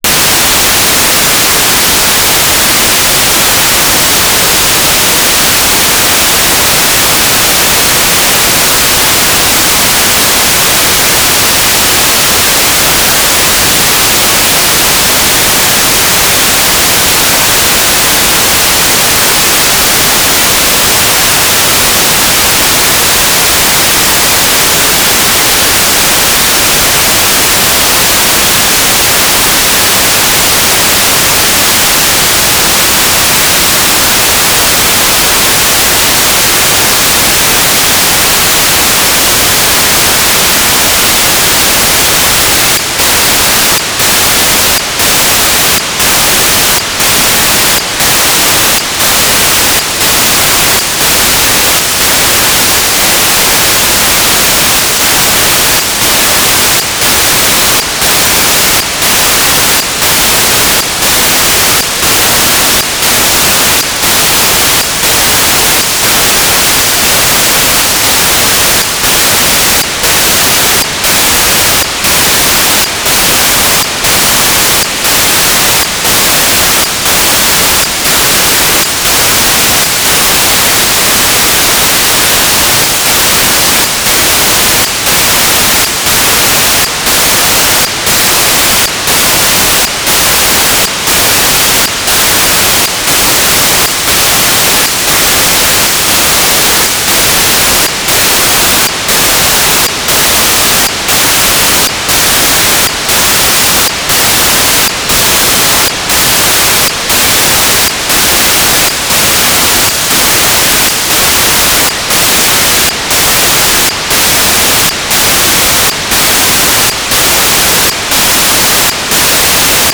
"transmitter_description": "Mode U - SSDV",